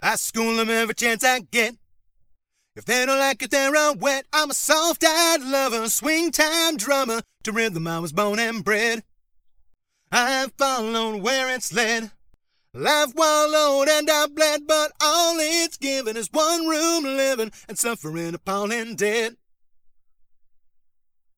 Singing